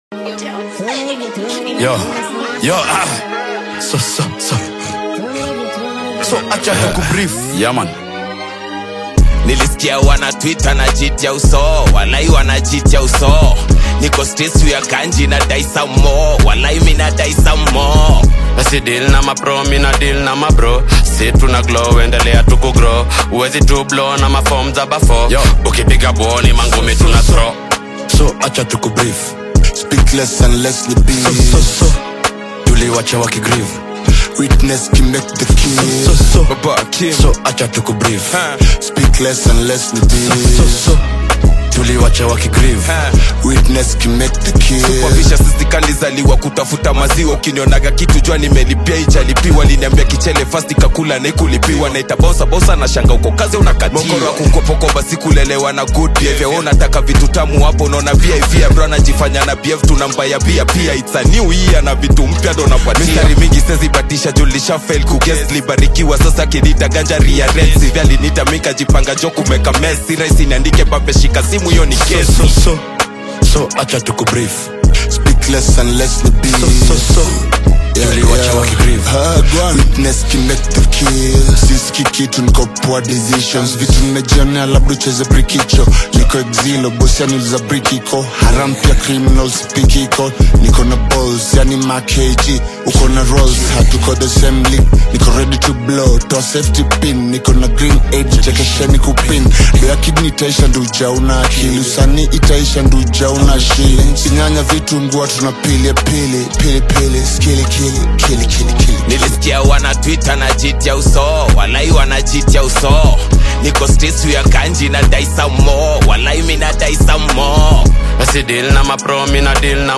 is a lively Bongo Flava/Afro-Fusion single